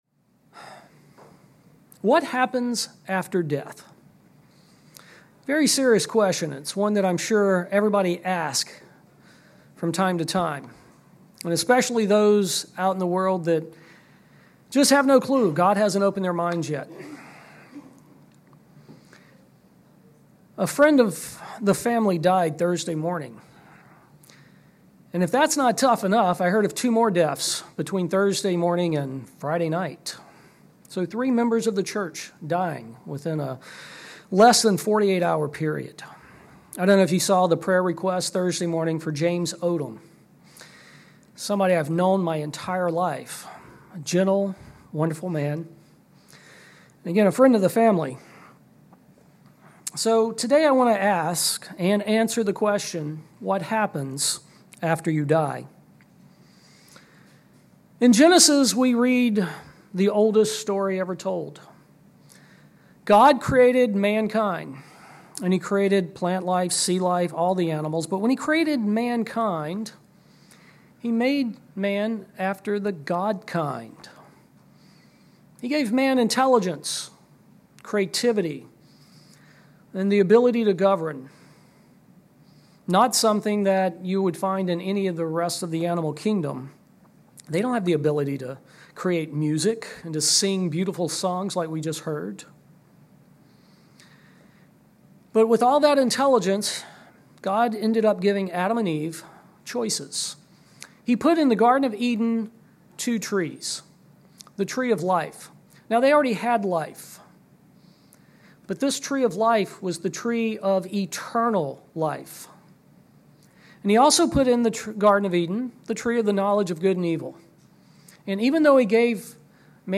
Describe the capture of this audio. Given in Fort Worth, TX